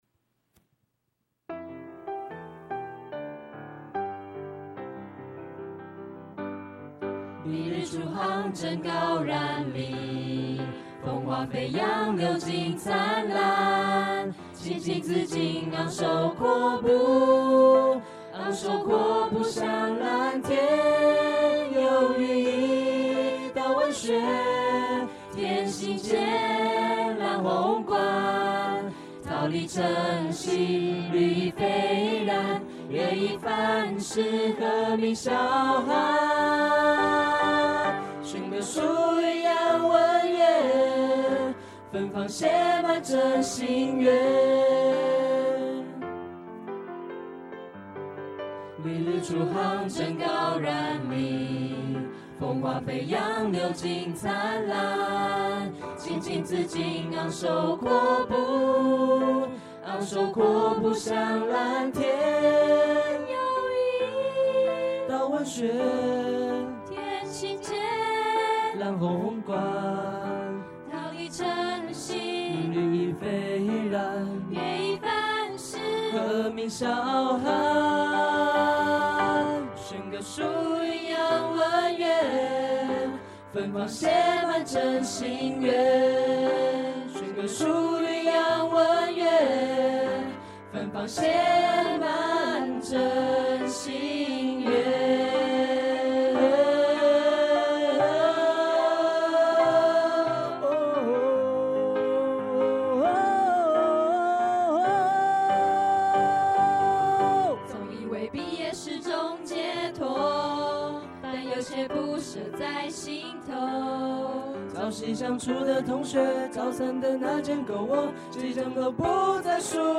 完成這首端正典雅又不失青春活力的校歌旋律平和中見動感，容易琅琅上口。